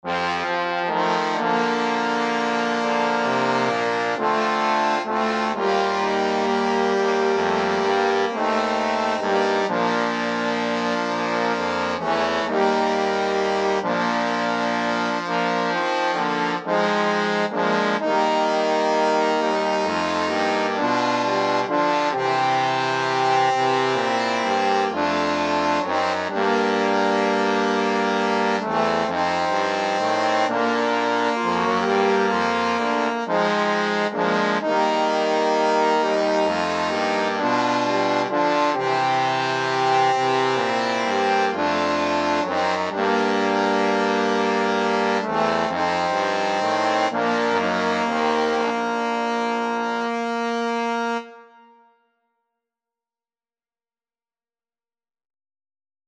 • 5 ľudových piesní